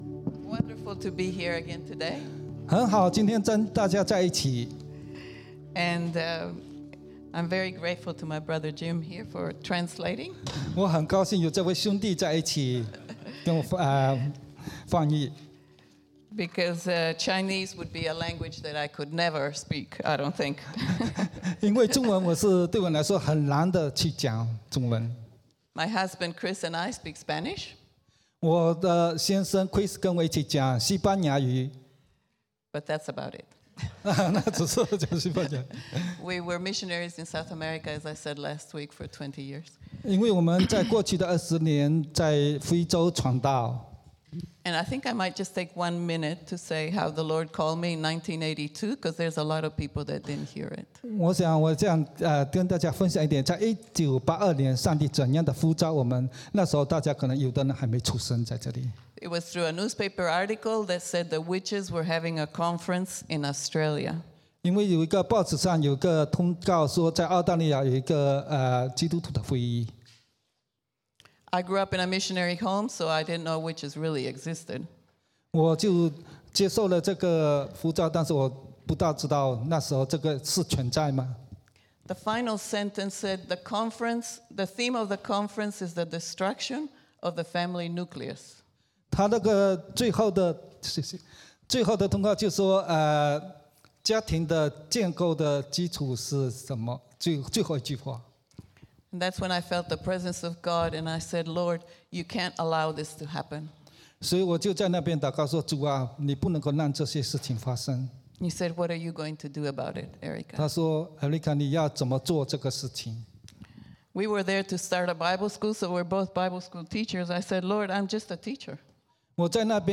English Sermons | Casey Life International Church (CLIC)
Bilingual Worship Service - 13th November 2022